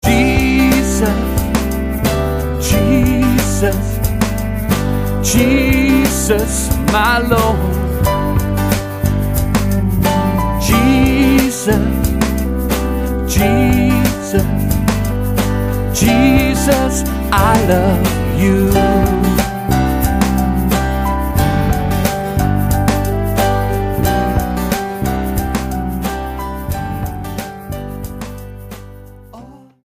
STYLE: Roots/Acoustic
Guitar-based, but also including some appealing keyboards.